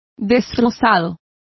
Complete with pronunciation of the translation of shattered.